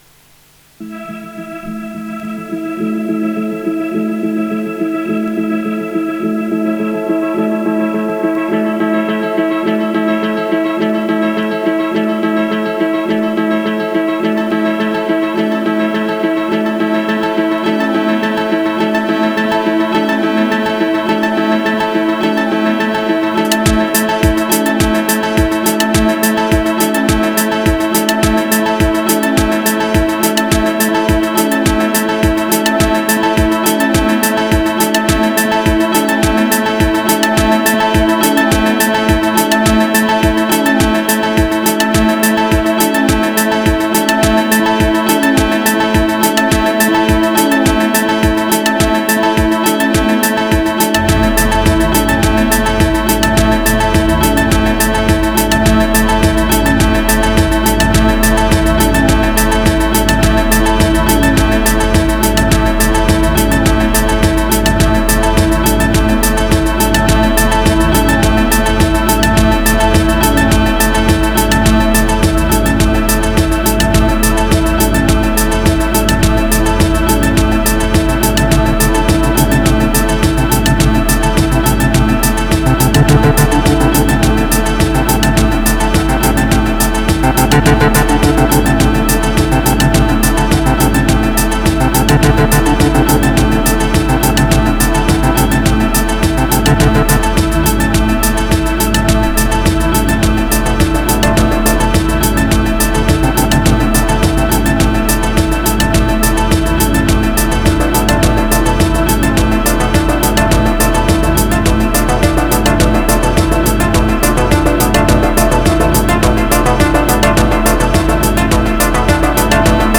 520📈 - 79%🤔 - 105BPM🔊 - 2025-08-17📅 - 568🌟